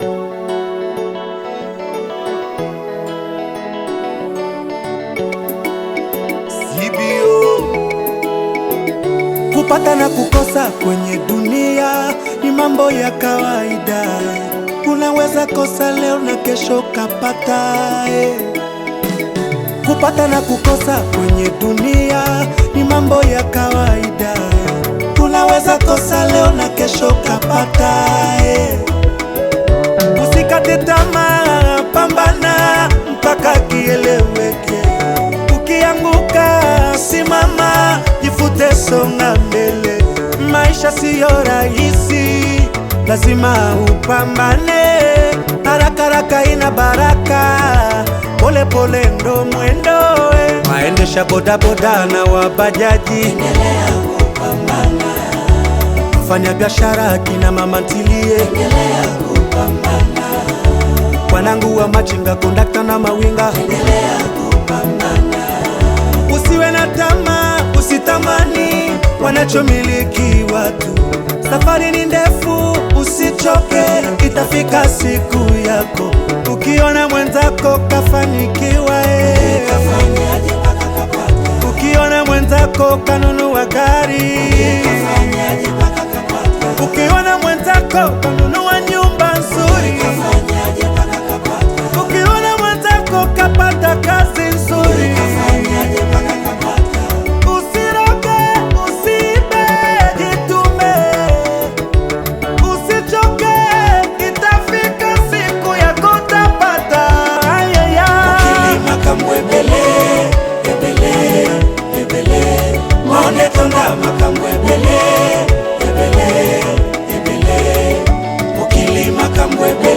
AudioRhumba
uplifting Afro-Beat single